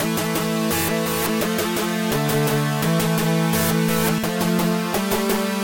混合低音
描述：低音合成器循环
标签： 85 bpm Funk Loops Bass Synth Loops 972.84 KB wav Key : E Cubase
声道立体声